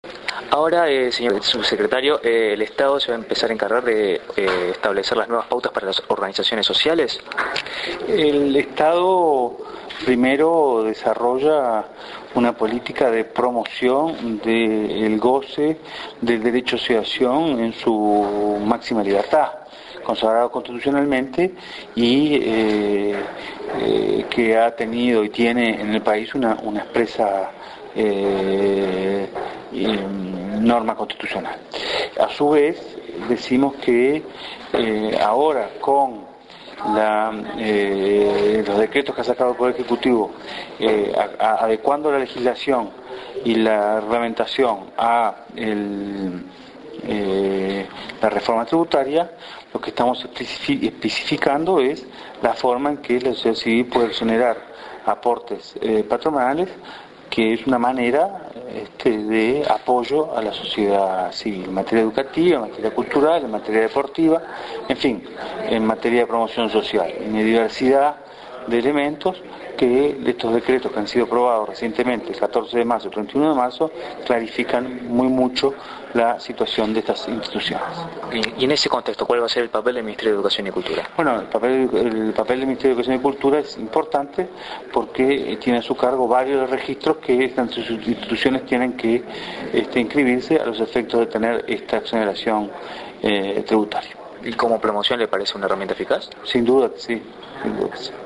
Declaraciones a la prensa del Subsecretario de Educación y Cultura, Felipe Michelini, en la charla – taller sobre la importancia y los beneficios de incorporar prácticas de transparencia y rendición de cuentas en la vida cotidiana de las organizaciones de la sociedad civil.